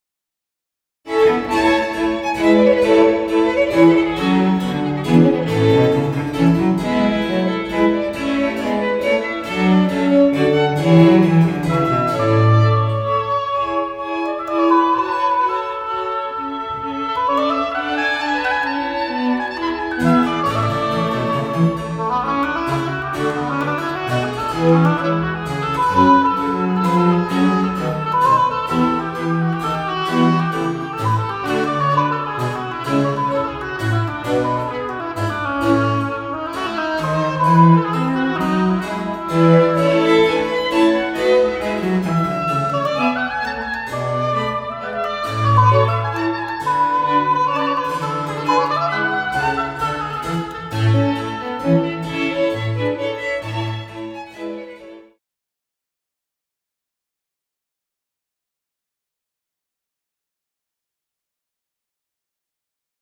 6th International Chamber Music Festival – Tuscany
Concerts recorded live